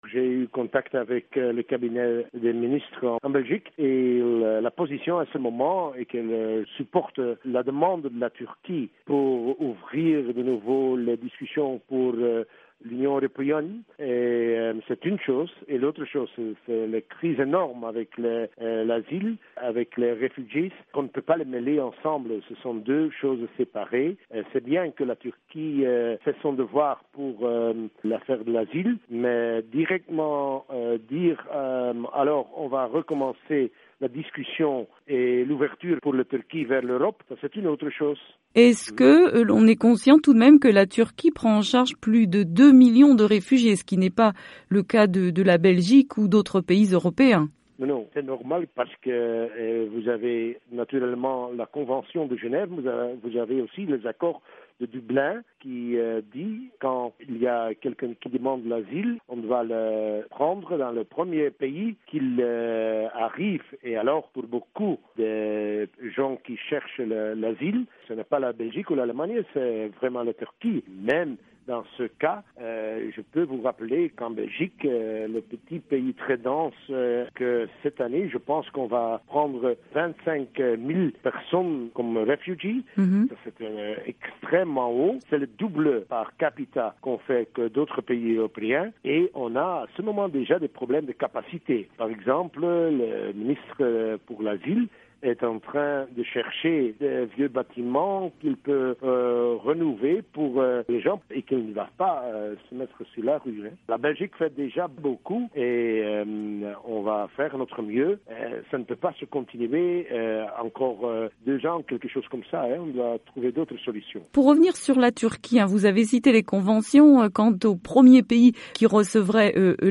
Le Sénateur belge Driessche joint à Genève